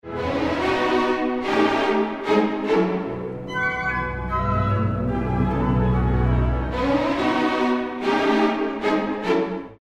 Allegro molto vivace, sol maggiore, C (12/8)
complemetare   bt. 96-110 violini,
poi archi e fiati   18   esempio 18 di partitura (formato PDF)
esempio 18 orchestrale (formato MP3) esempio multimediale (formato formato flash)